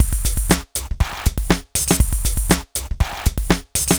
The Tech (Drums) 120BPM.wav